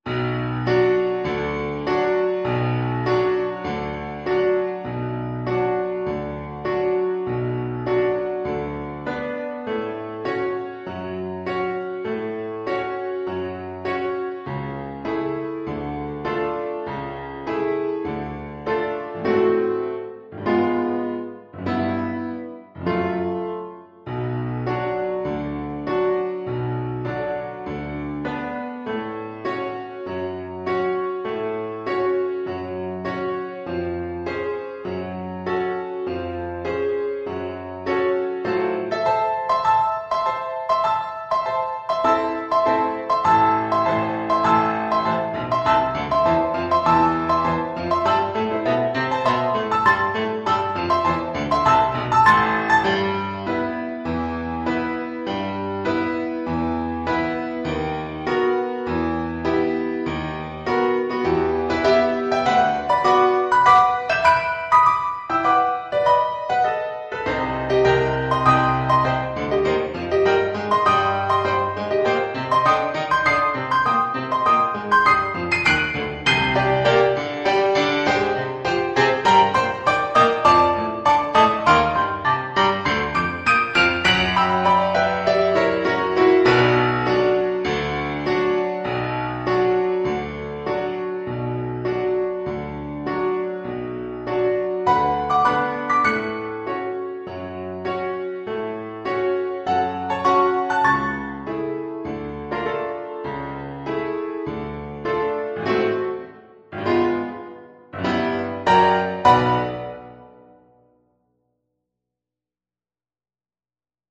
Classical (View more Classical Flute Music)